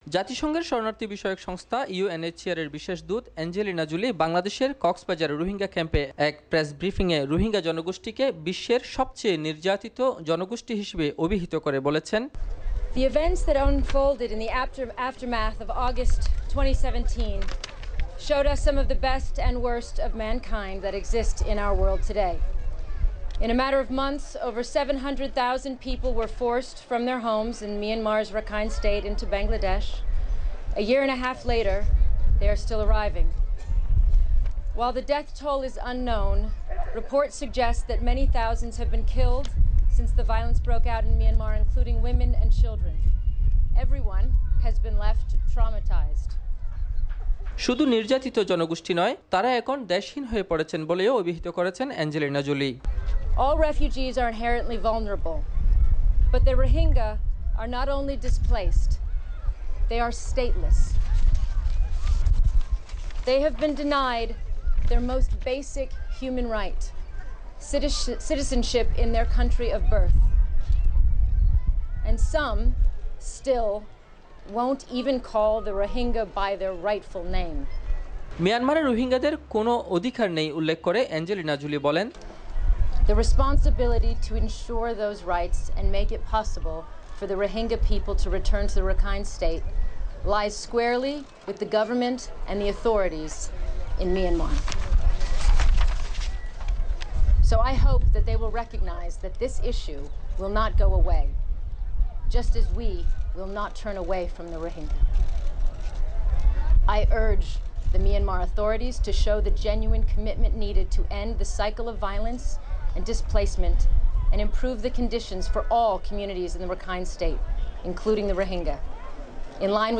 জাতিসংঘের শরণার্থী বিষয়ক সংস্থা ‘ইউএনএইচসিআর’র বিশেষ দূত অ্যাঞ্জেলিনা জোলি বাংলাদেশের কক্সবাজার রোহিঙ্গা ক্যাম্পে এক প্রেস ব্রিফিং-এ রোহিঙ্গা জনগোষ্ঠীকে বিশ্বের সবচেয়ে নির্যাতিত জনগোষ্ঠী হিসেবে অবিহিত করে বলেছেন,শুধু নির্যাতিত জনগোষ্ঠী নয়, তারা এখন দেশহীন হয়ে পড়েছেন।